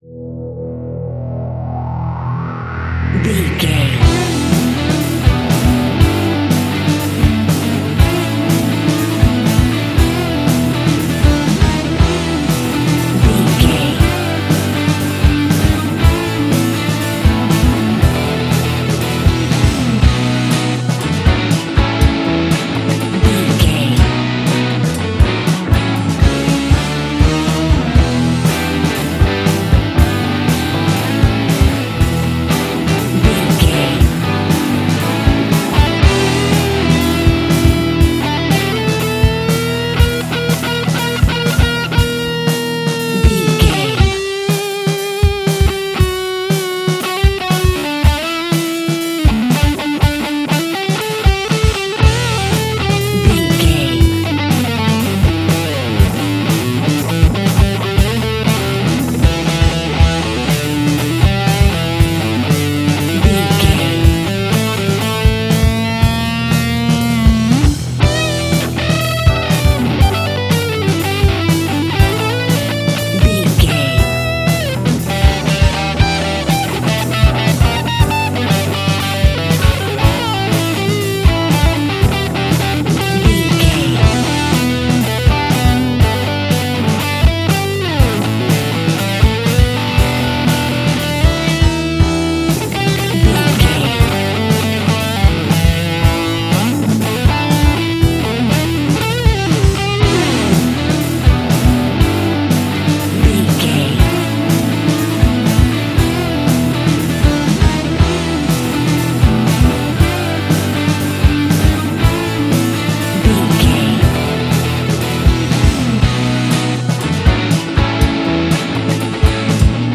Aeolian/Minor
hard
groovy
powerful
electric guitar
bass guitar
drums
organ
80s